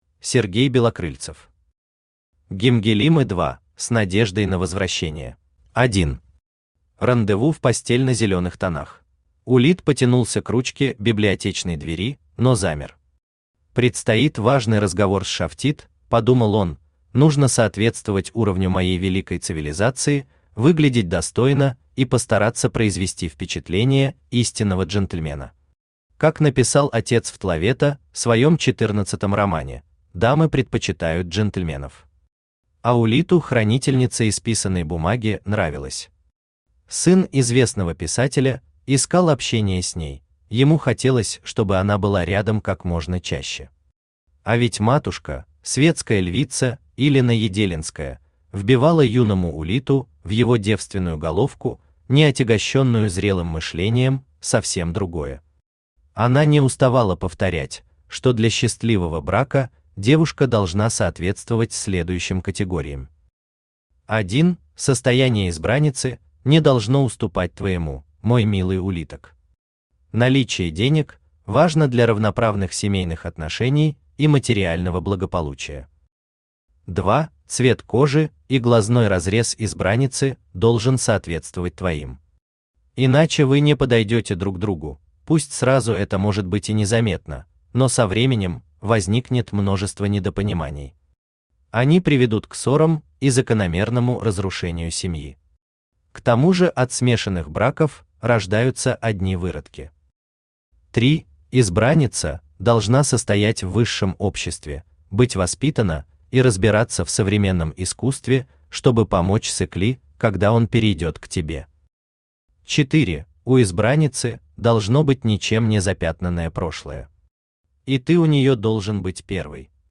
Автор Сергей Валерьевич Белокрыльцев Читает аудиокнигу Авточтец ЛитРес.